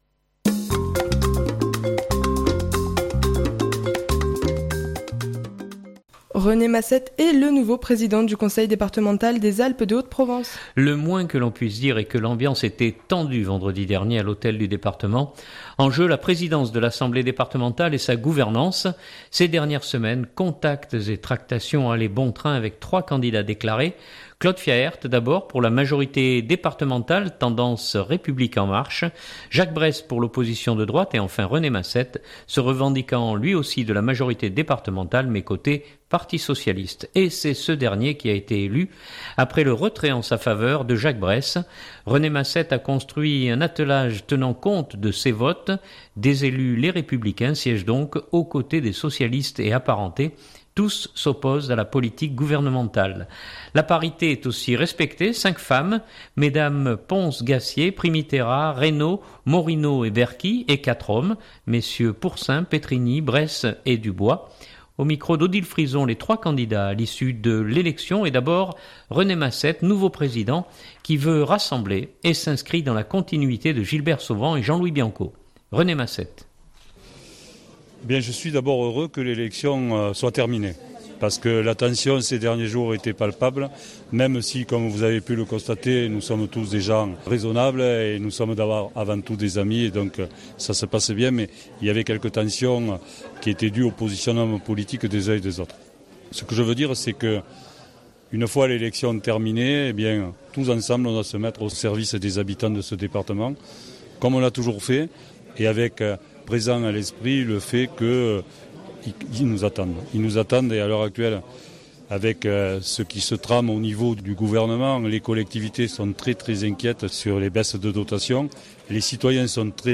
Le moins que l’on puisse dire est que l’ambiance était tendue vendredi dernier à l’hôtel du Département.